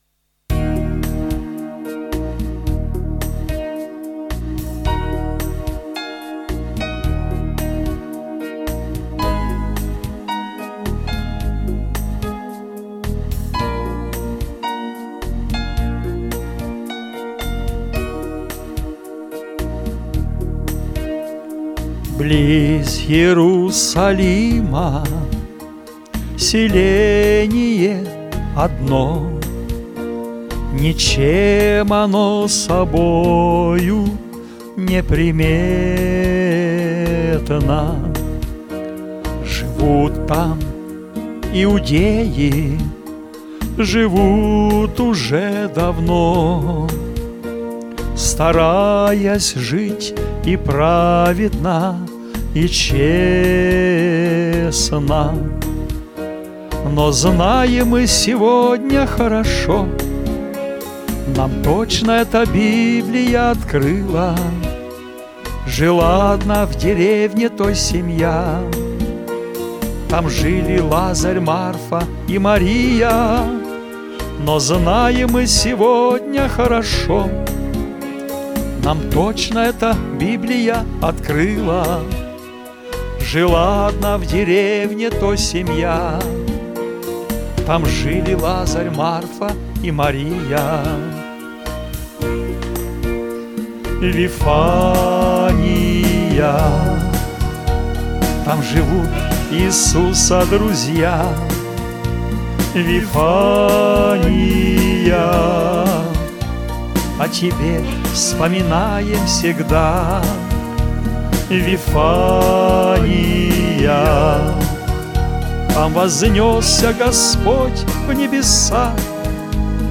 Категория: Авторские песни.
Христианские песни